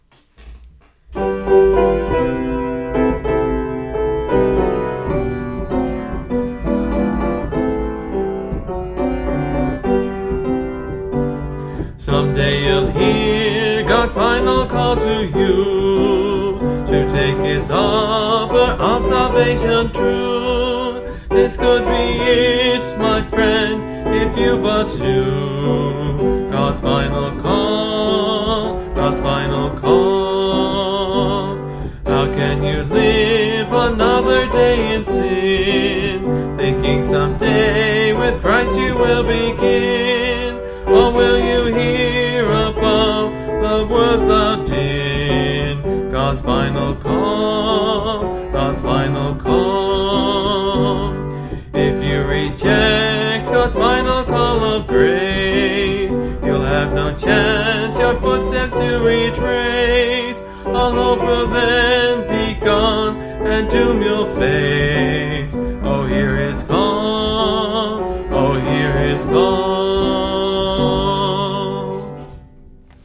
Music, Uncategorized 1 Minute
(Part of a series singing through the hymnbook I grew up with: Great Hymns of the Faith)